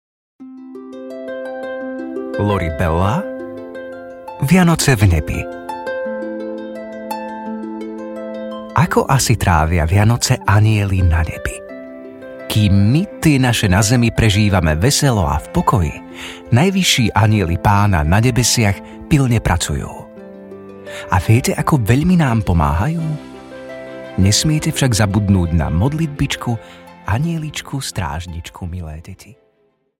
Vianoce v nebi audiokniha
Ukázka z knihy